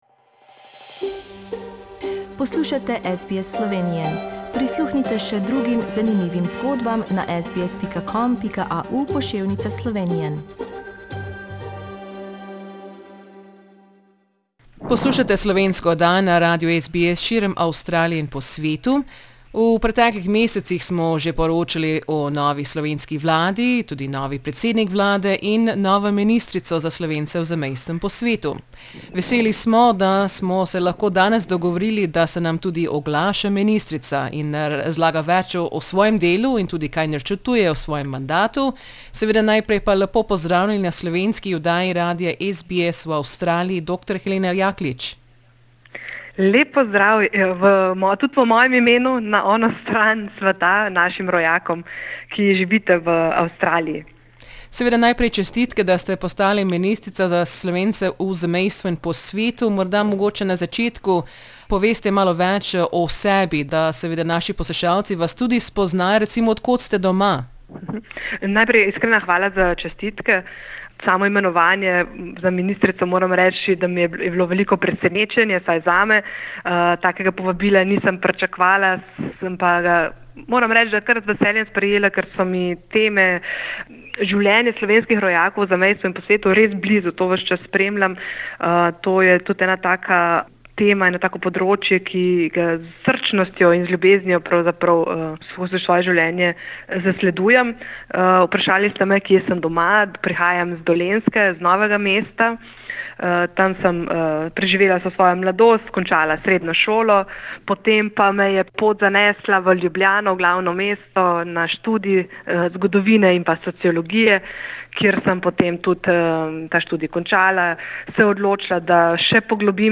Pogovarjali smo se z novo ministrico za Slovence v zamejstvu in po svetu Dr. Heleno Jaklitsch.